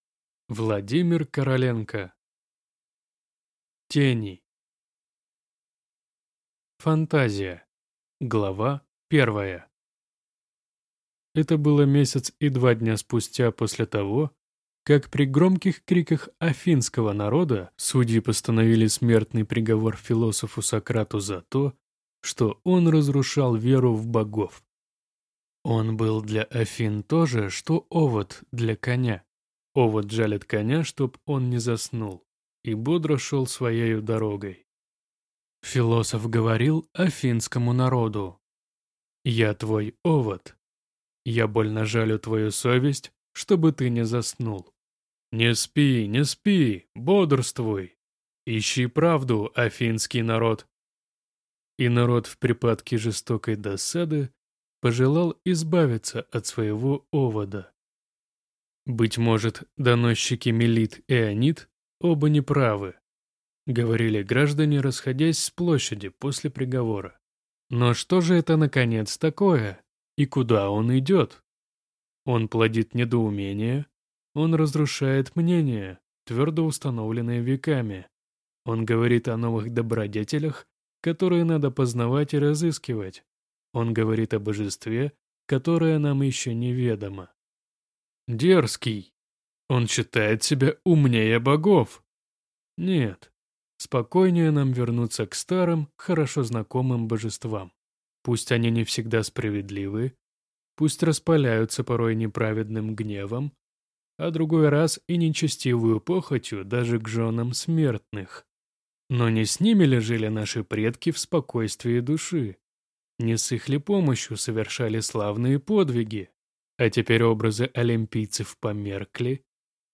Аудиокнига Тени | Библиотека аудиокниг